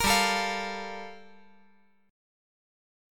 Gm9 Chord
Listen to Gm9 strummed